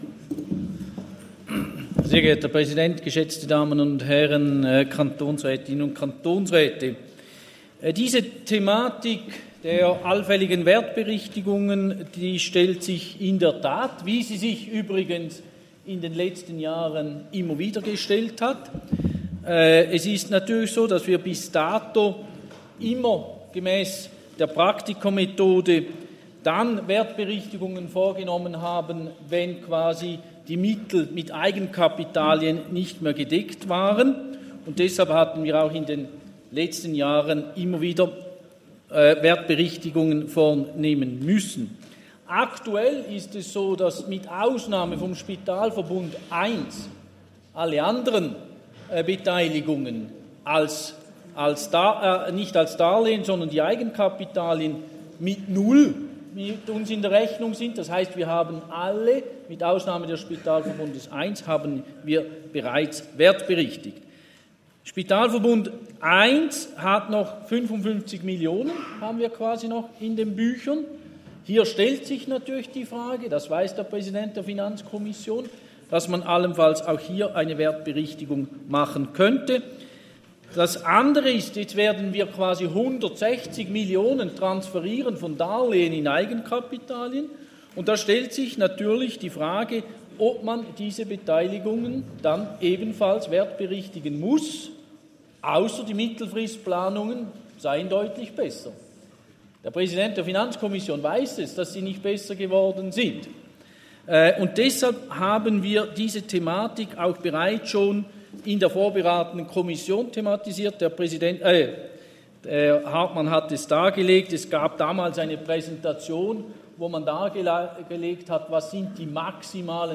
Session des Kantonsrates vom 13. bis 15. Februar 2023, Frühjahrssession